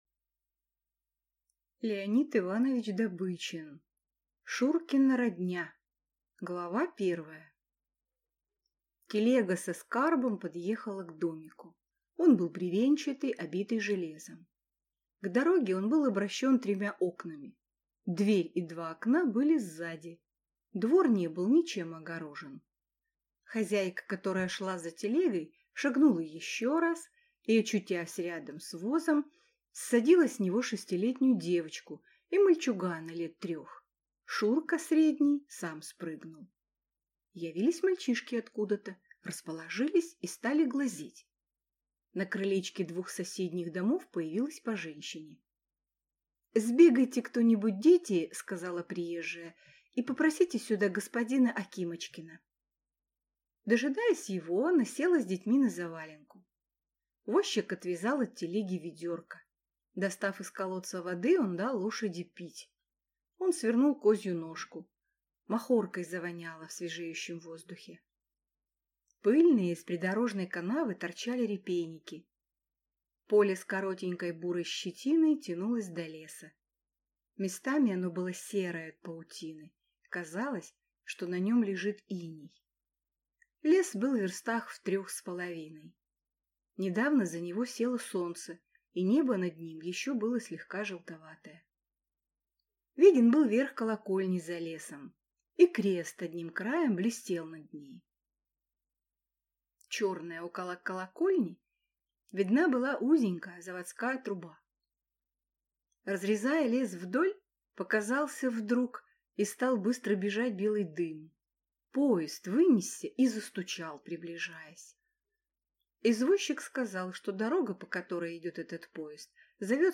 Аудиокнига Шуркина родня | Библиотека аудиокниг